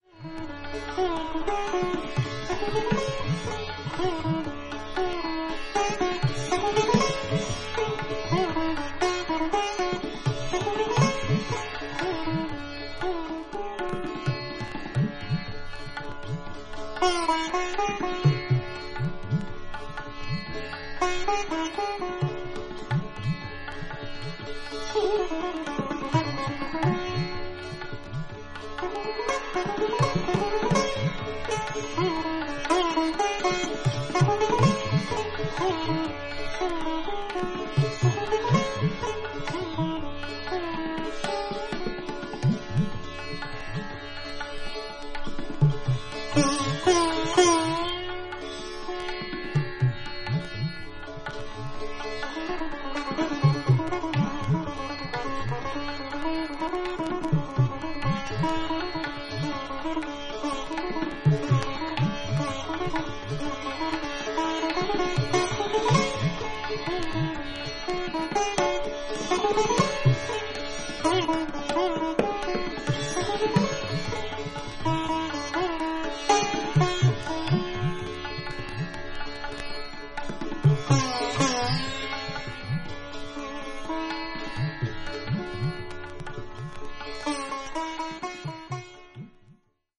男性奏者が多かったこの分野で、最も優れた女性奏者の一人とみなされている、卓越された演奏が堪能できます。
タブラ
タンブラ